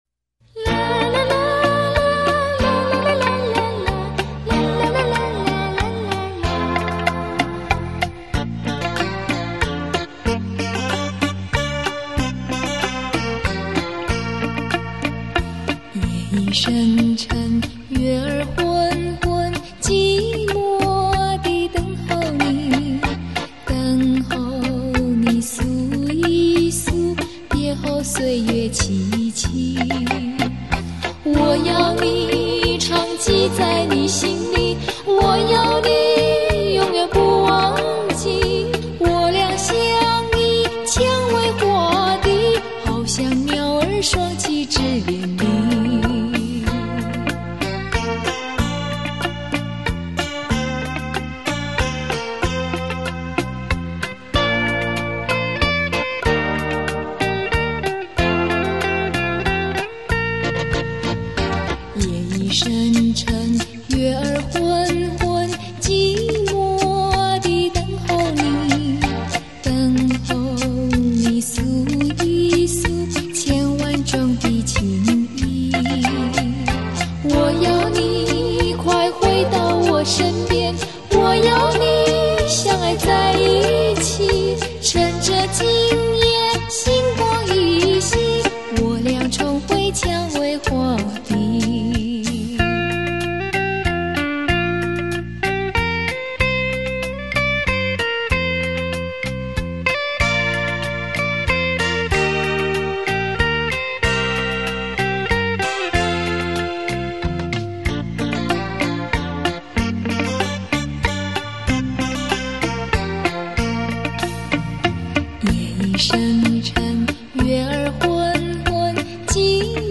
动听的歌声， 如梦般的永恒的旋律